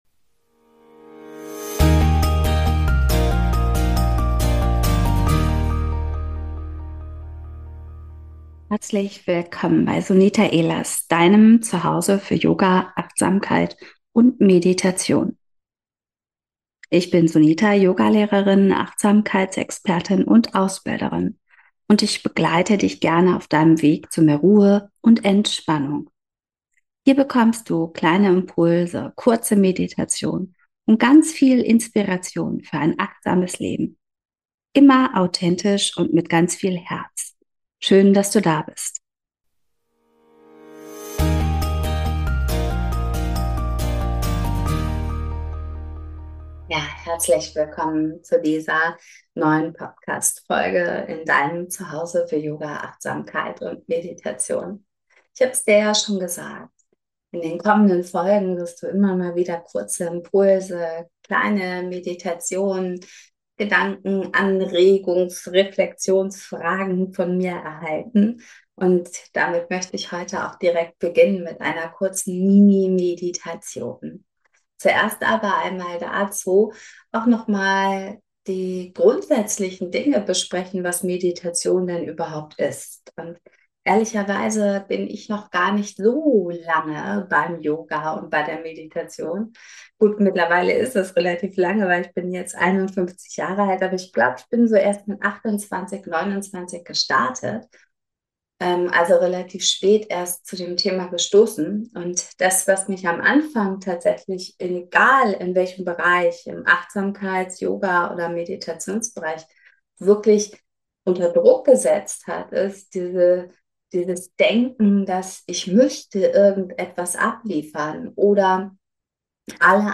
Eine kurze Meditation für dich